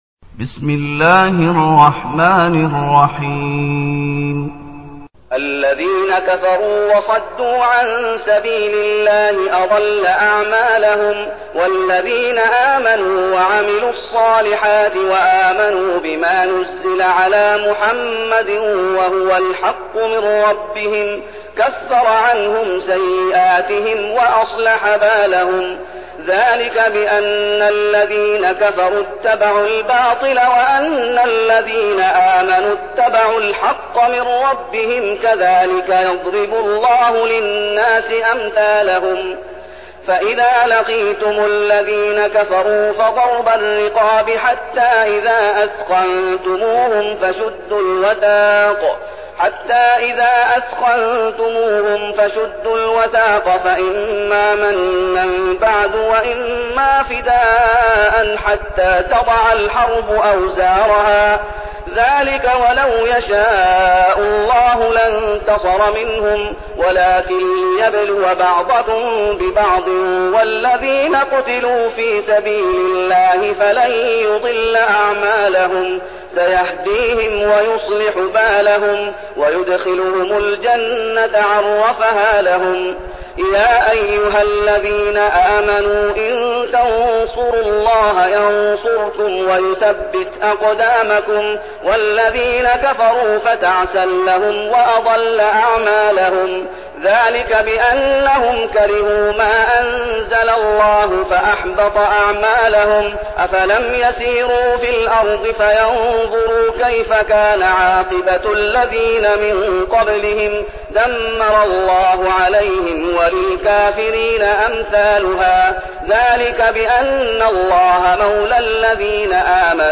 المكان: المسجد النبوي الشيخ: فضيلة الشيخ محمد أيوب فضيلة الشيخ محمد أيوب محمد